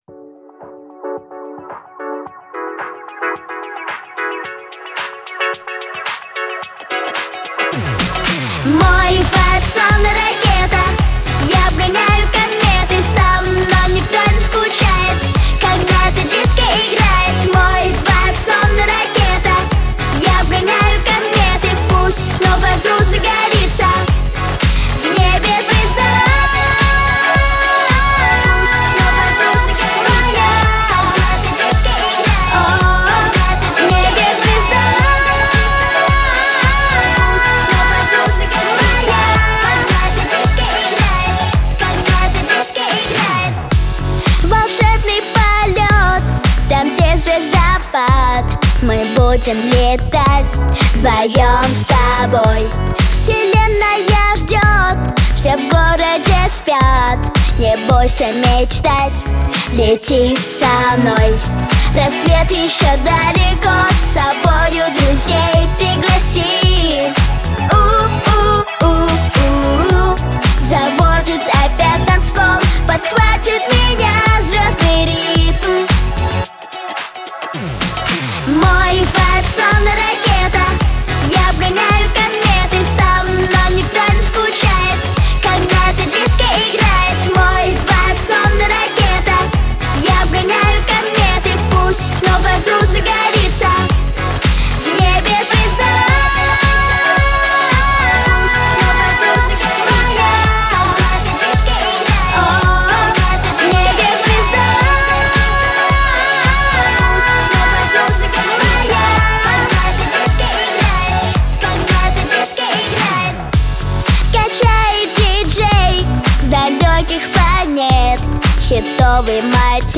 • Категория: Детские песни
диско, детская дискотека